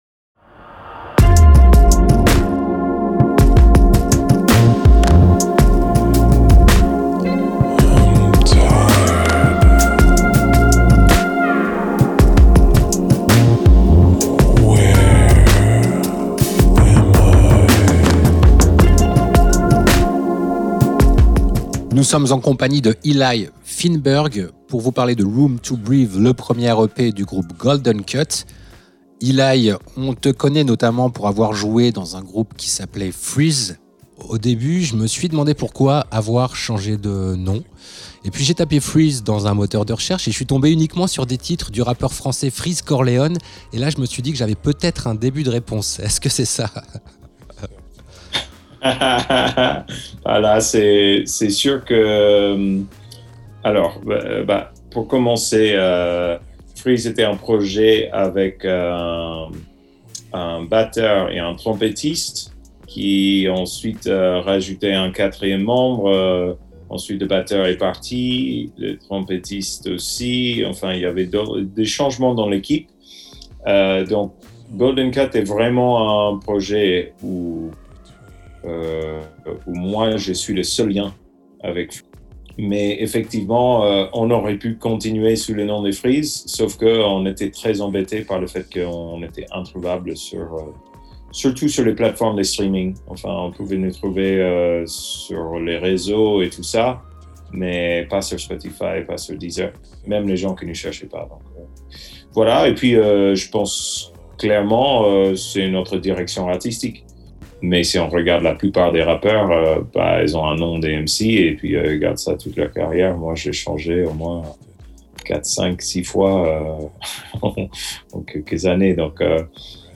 Interview GOLDEN CUT - Février 2022